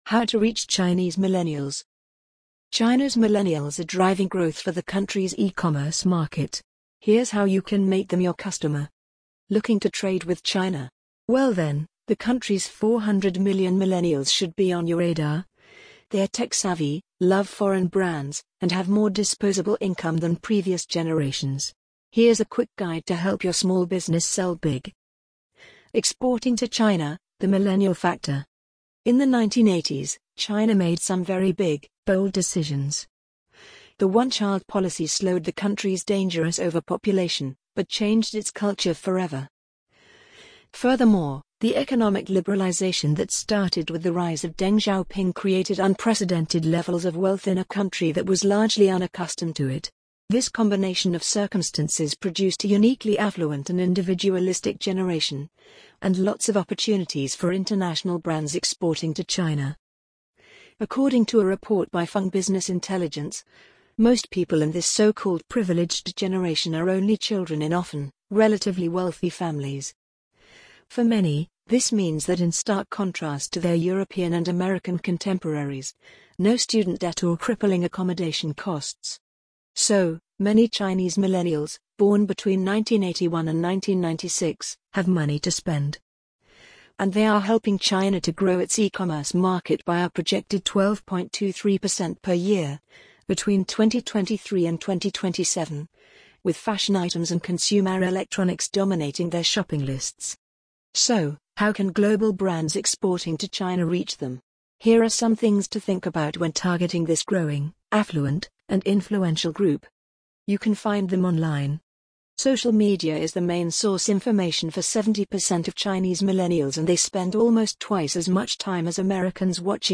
amazon_polly_46912.mp3